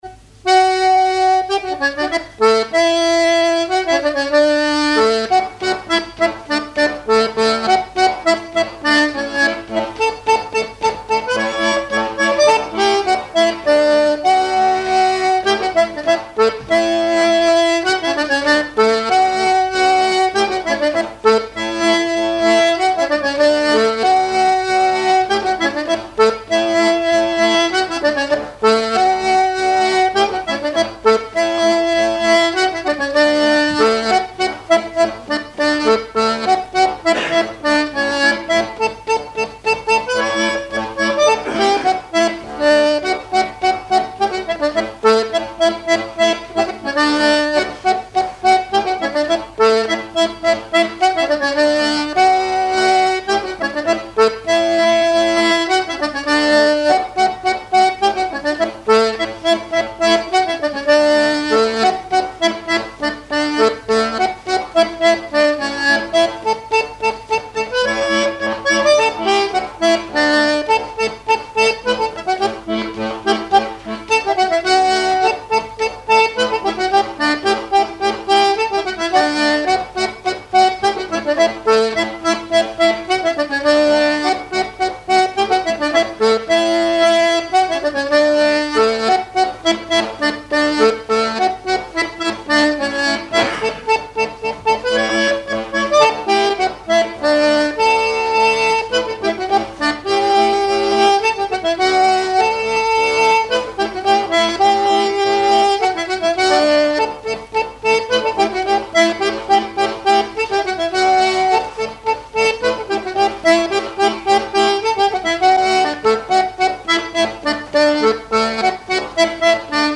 airs de danse à l'accordéon diatonique
Pièce musicale inédite